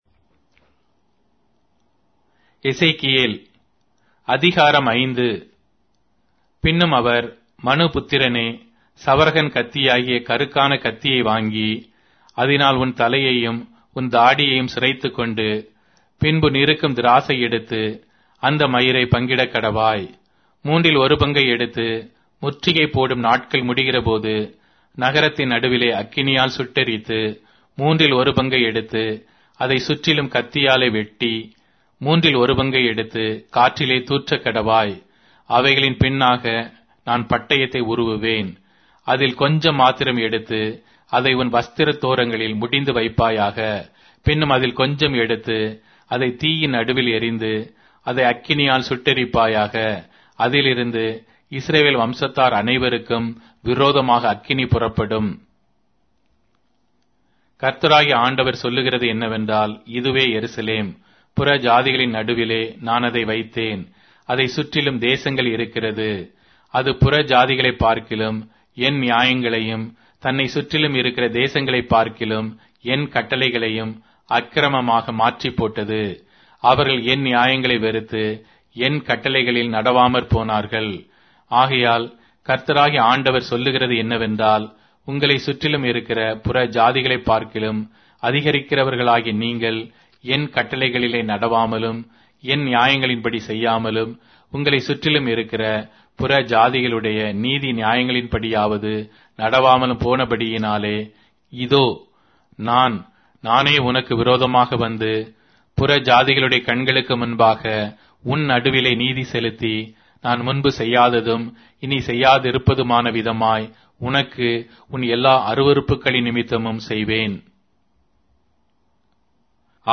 Tamil Audio Bible - Ezekiel 21 in Ylt bible version